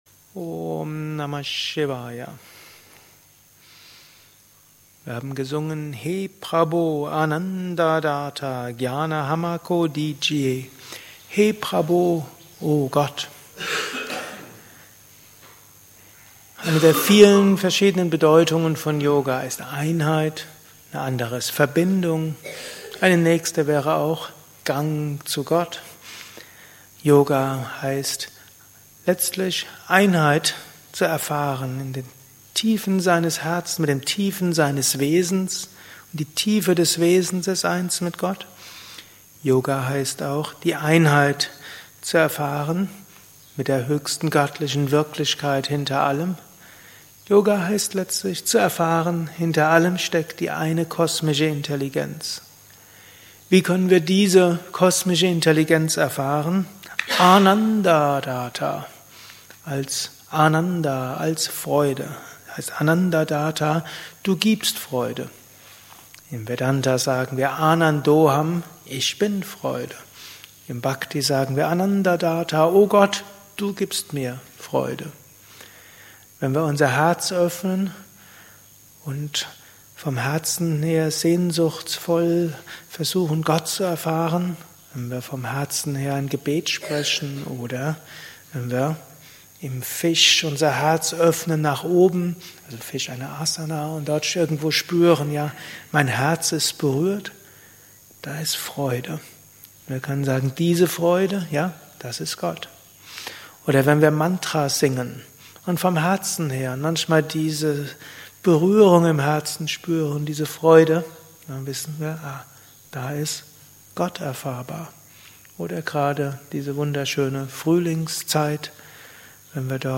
Kurzvorträge
Meditation im Yoga Vidya Ashram Bad Meinberg.